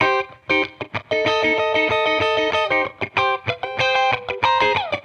Index of /musicradar/sampled-funk-soul-samples/95bpm/Guitar
SSF_TeleGuitarProc2_95C.wav